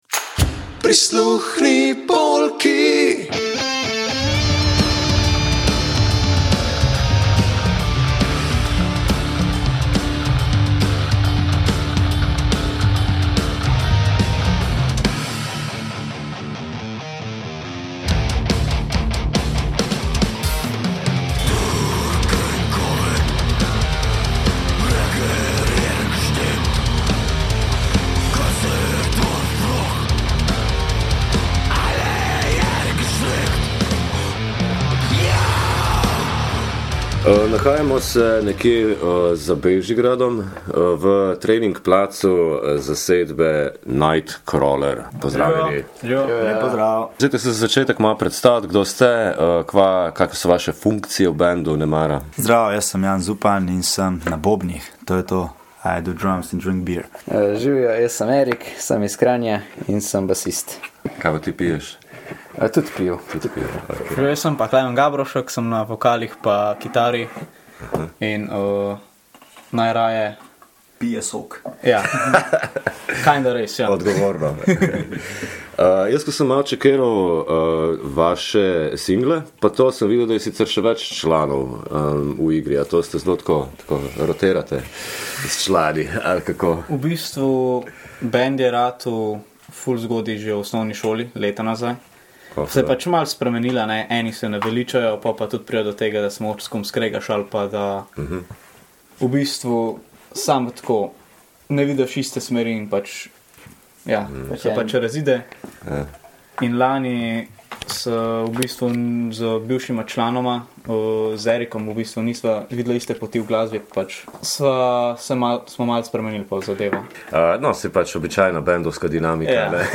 Na neko sončno protozimsko nedeljo smo v gosteh pri Night Crawler – ljubljanskem triu, ki preigrava metal. Med pogovorom razkrijejo začetke skupine, ki ima kljub mladosti članov že kar nekaj kilometrine in tudi bivših članov. Preverili smo tudi njihove odrske izkušnje in pobrskali, kaj se skriva za besedili in rifi.